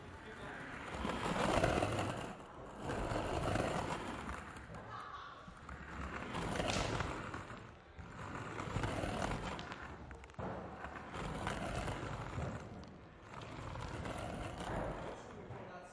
" Skateboard Rolling in Poo